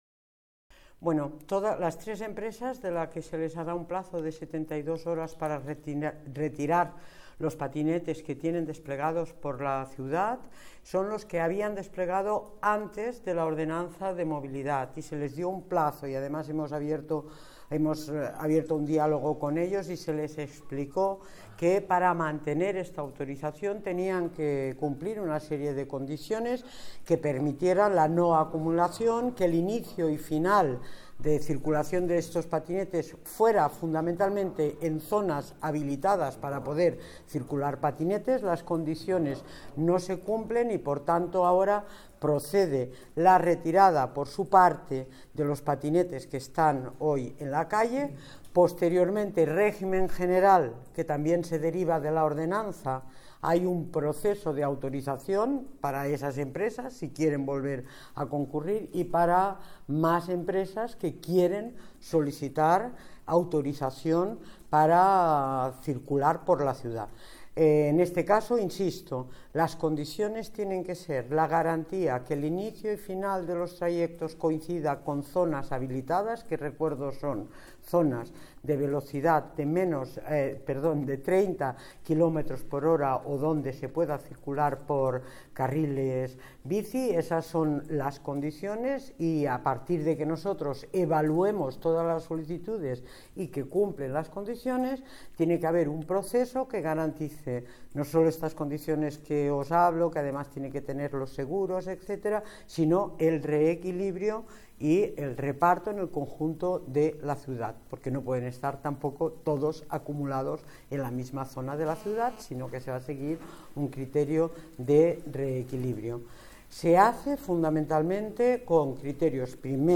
Nueva ventana:Inés Sabanés, delegada Medio Ambiente y Movilidad